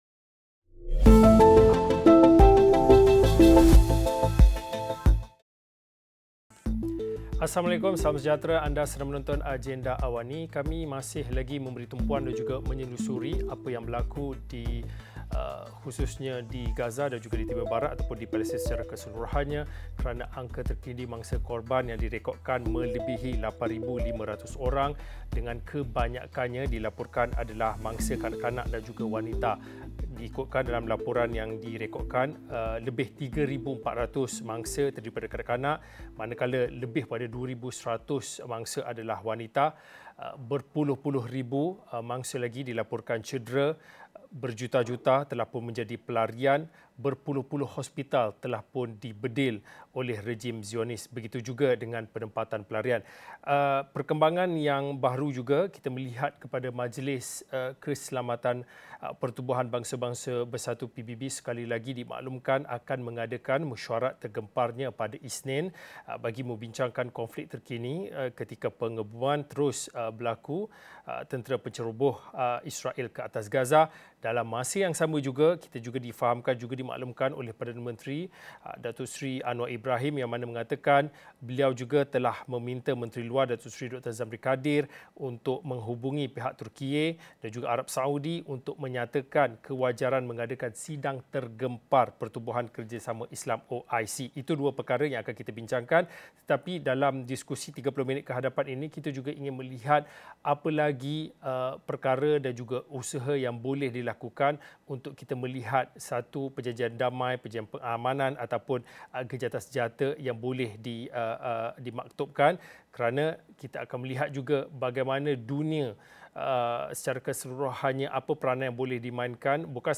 Sejauh mana dunia perlu bertindak dan terus menekan rejim Zionis Israel untuk tunduk kepada desakan gencatan senjata bagi mempermudah urusan bantuan kecemasan kemanusiaan, selain melindungi penduduk yang terperangkap dalam perang Israel-Palestin? Diskusi 9 malam.